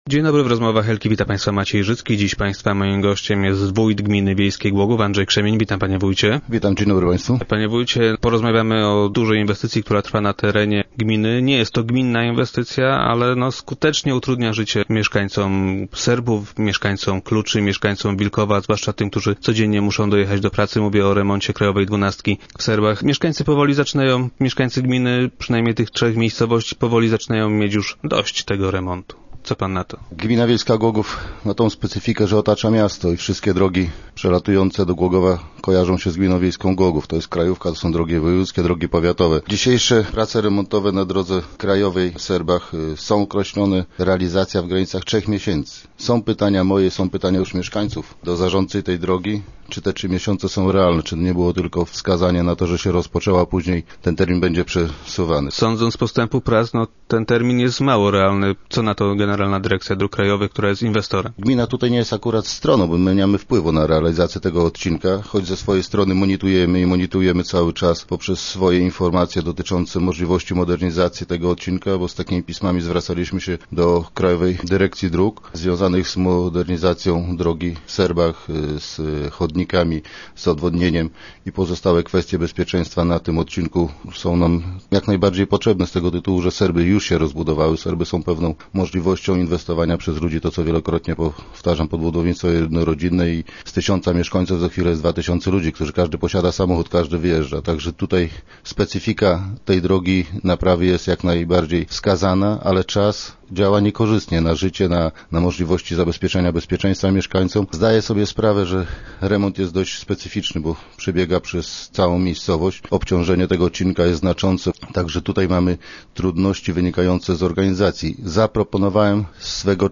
Jak stwierdził gość dzisiejszych Rozmów Elki, z władzami gminy nikt nie uzgadniał przebiegu remontu i jego zakresu.